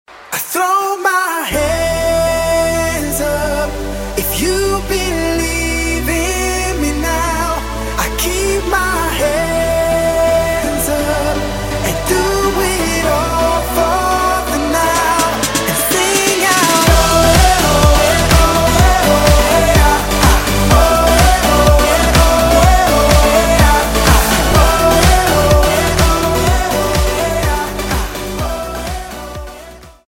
поп
громкие
dance
vocal
dance hall